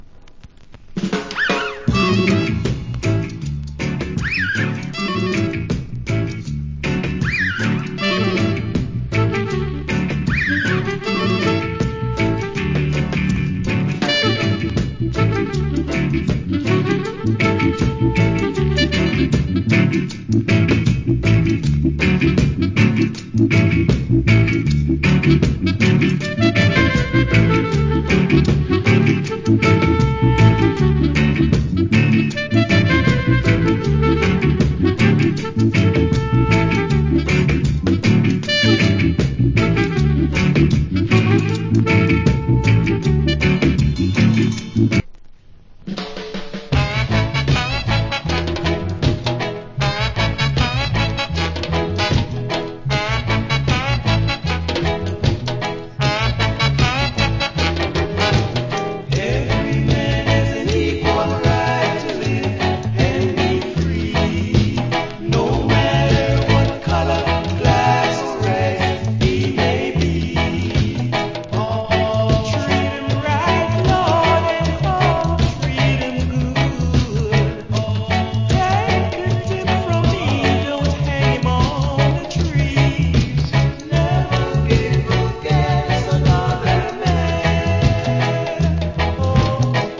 Cool Early Reggae Inst.